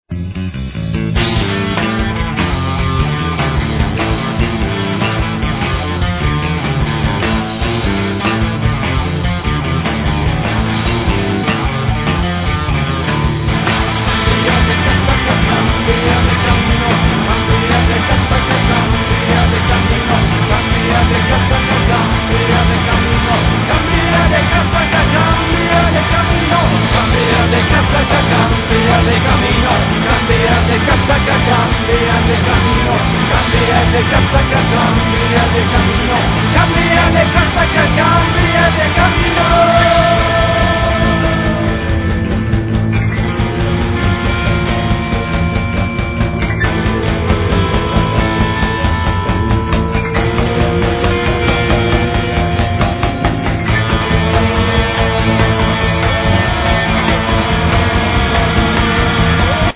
Guitars, Vocals, Percussions, Handclap, Echoes
Bass, Vocals, Acoustic guitar, Piano, Percussions
Drum kit, Vocals, Paino, Percussions, Handclap
Piano, Organ, Percussions, Snap